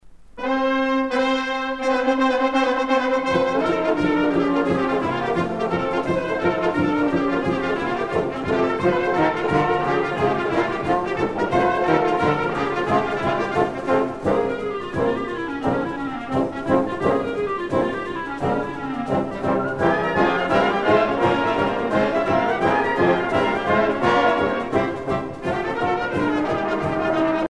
danse : horo (Bulgarie)
Pièce musicale éditée